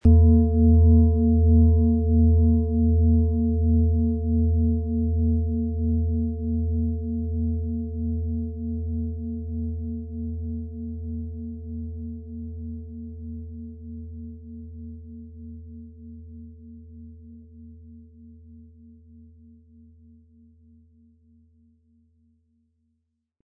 Handgearbeitete tibetische Schale mit dem Planetenton Hopi-Herzton.
• Tiefster Ton: Mond
• Höchster Ton: Wasserstoffgamma
PlanetentöneHopi Herzton & Mond & Wasserstoffgamma (Höchster Ton)
MaterialBronze